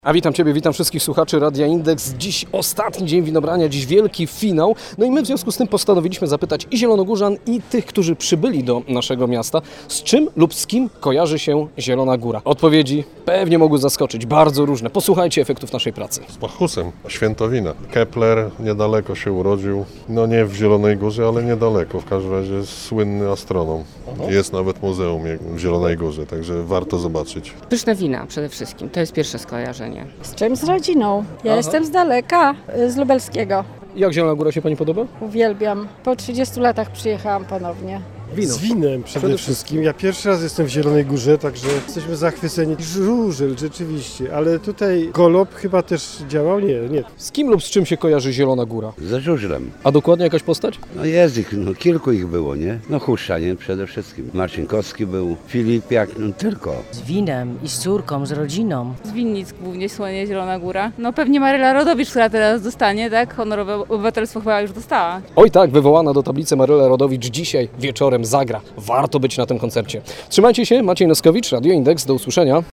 Polegał on na przepytaniu przechodniów z czym kojarzy im się nasze miasto.